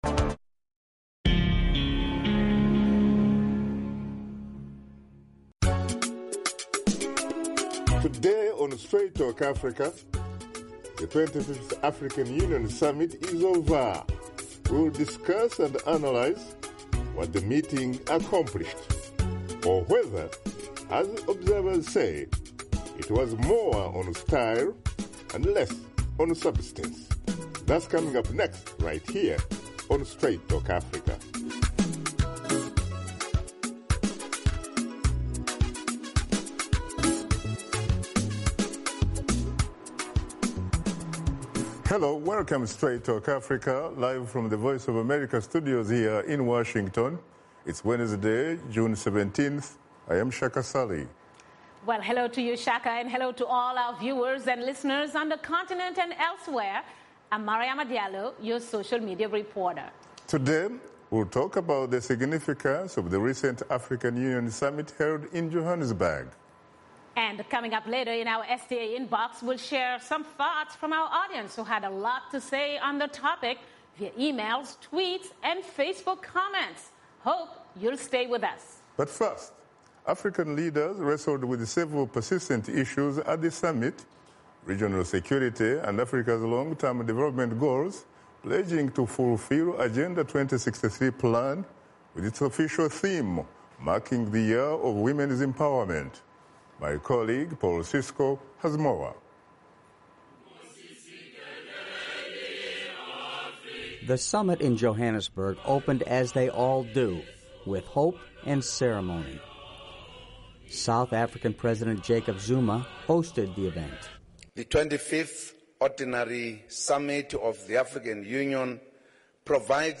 Host Shaka Ssali and his guests discuss the outcome of the 25th Ordinary Summit of the African Union held in Johannesburg, South Africa.